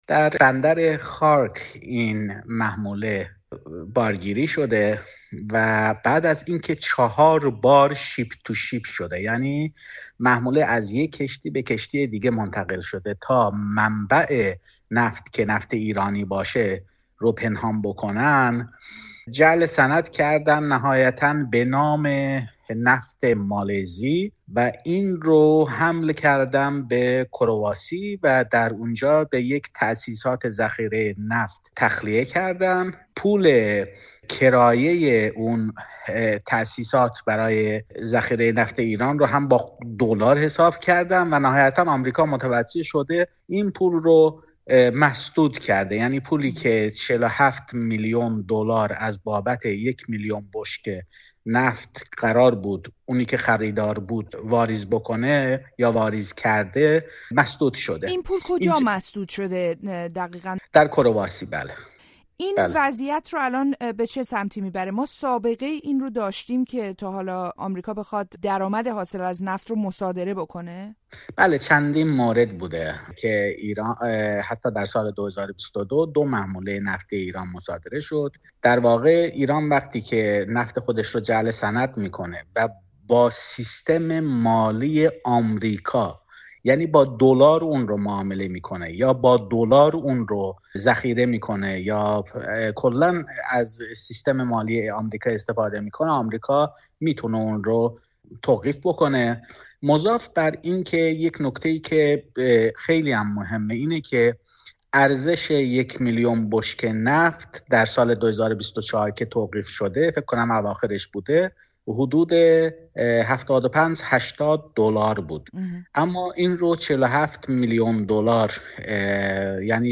مصادرهٔ درآمد یک میلیون بشکه نفت ایران در گفت‌وگو با یک کارشناس انرژی